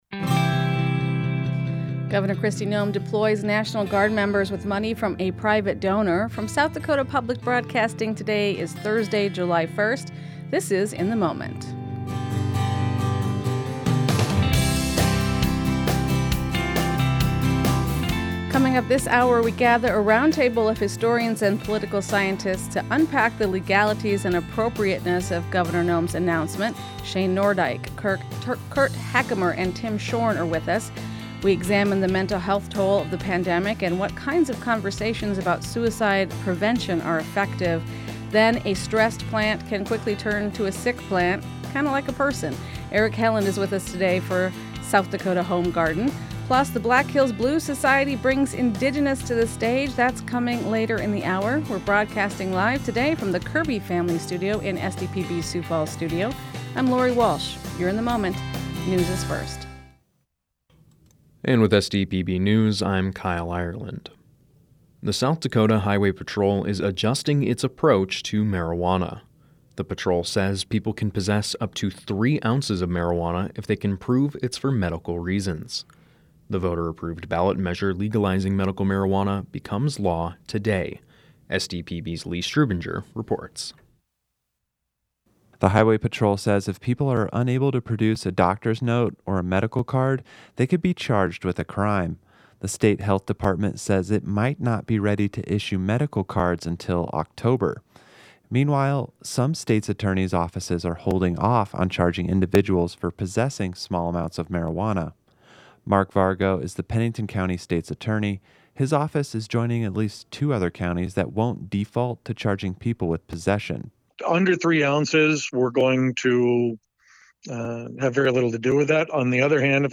In The Moment: Expert Roundtable Analyzes Noem Sending National Guard To Border
In the Moment is SDPB’s daily news and culture magazine program.